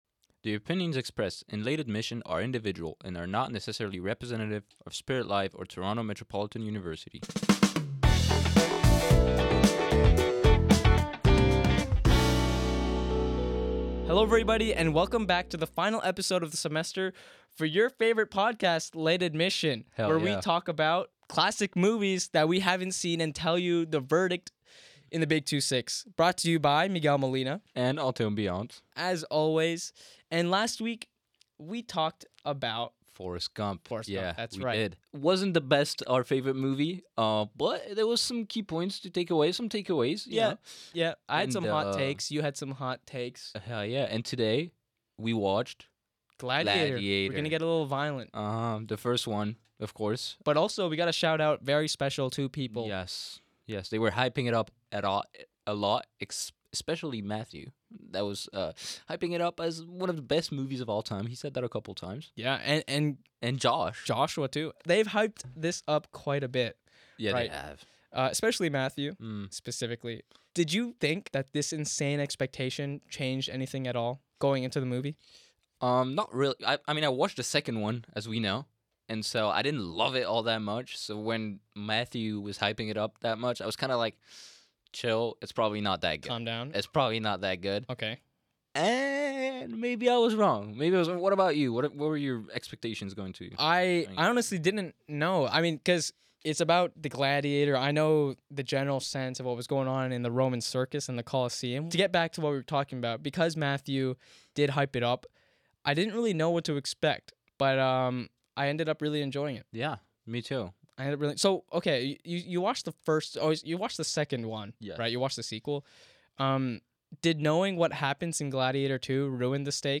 Two friends embark on a mission to watch the cinematic masterpieces they somehow missed.